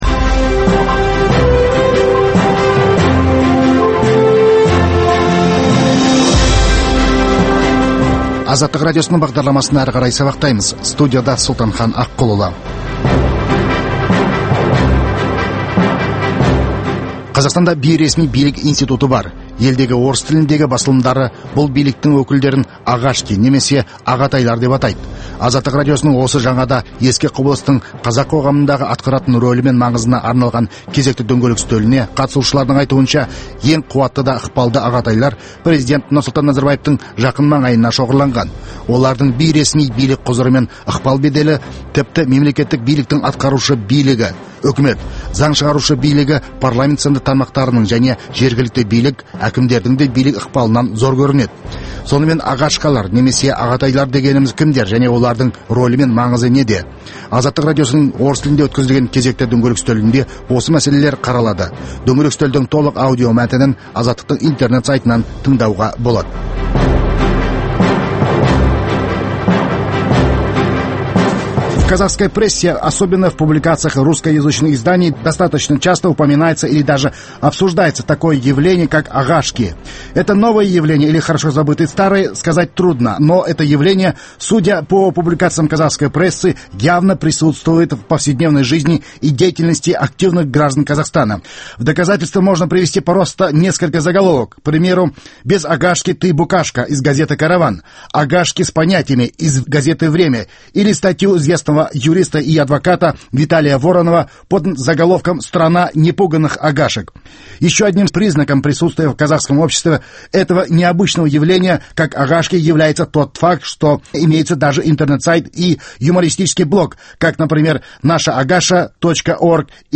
Дөңгелек үстел
Сонымен «агашкалар» немесе «ағатайлар» дегеніміз кімдер және олардың рөлі мен маңызы неде? Азаттық радиосының орыс тілінде өткізілген кезекті дөңгелек үстелінде осы мәселелер қаралады.